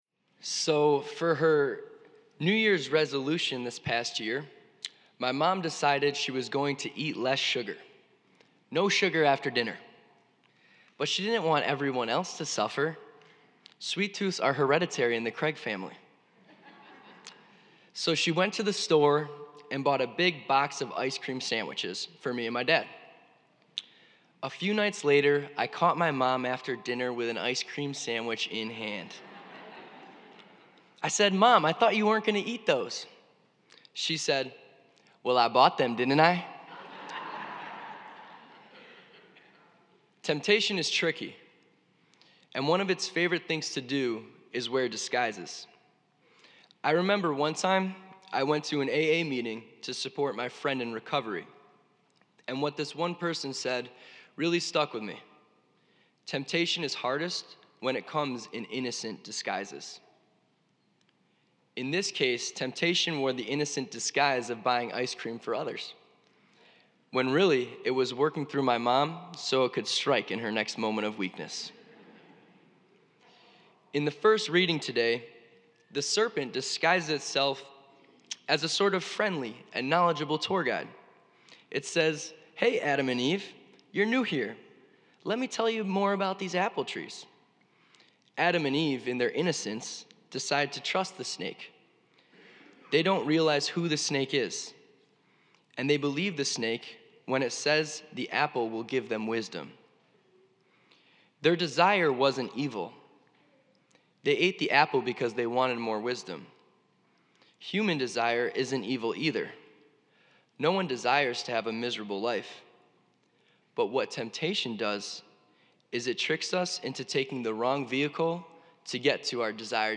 preaches to stay grounded in God's love when we are being tempted to believe we are not enough. This lent, when temptation comes to knock on your door, just tell it sorry, this is God's house.